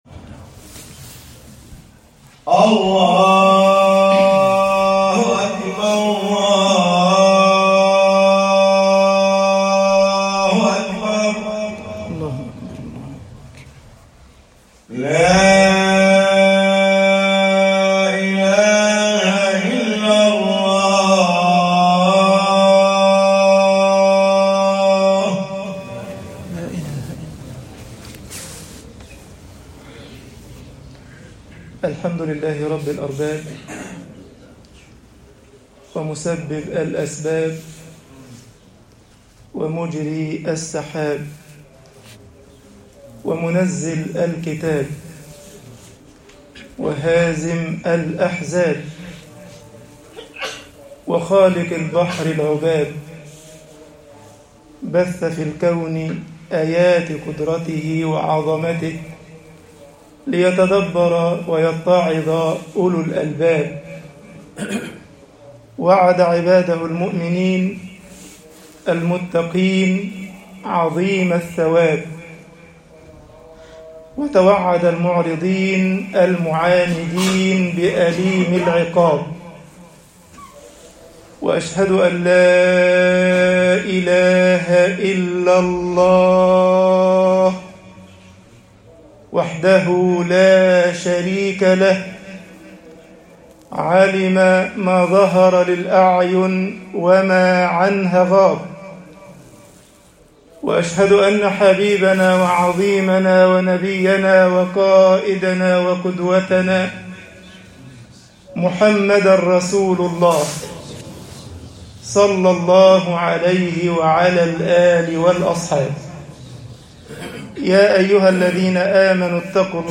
خطب الجمعة - مصر الْعَابِدُونَ فِي الْهَرْجِ قَلِيلُونَ طباعة البريد الإلكتروني التفاصيل كتب بواسطة